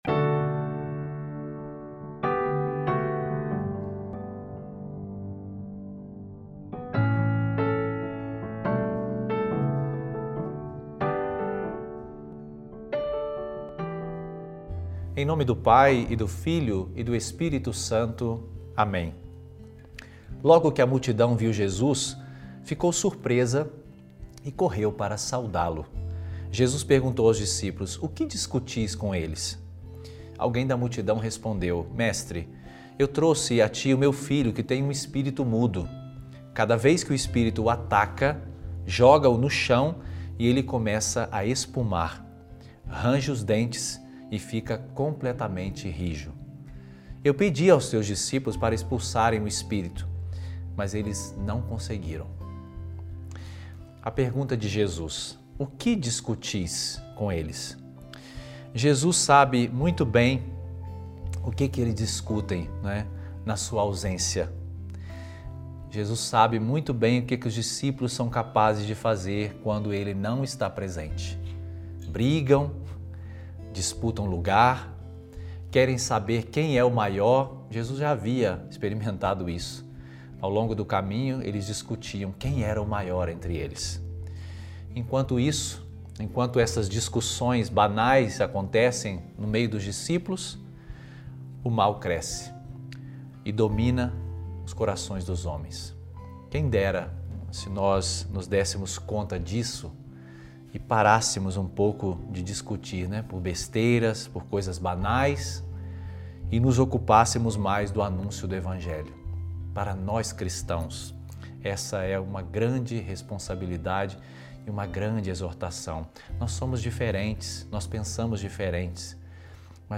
Reflexão sobre o Evangelho